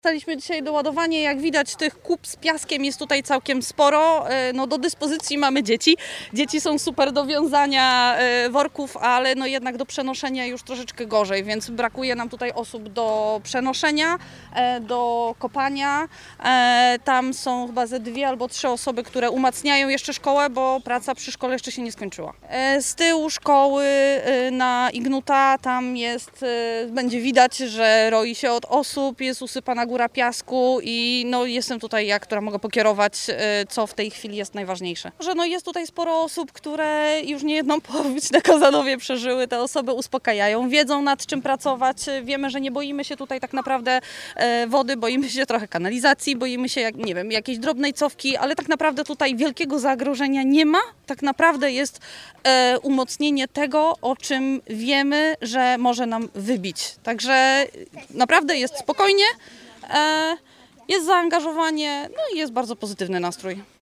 – Ręce do pracy są cały czas potrzebne – mówi mieszkanka Kozanowa.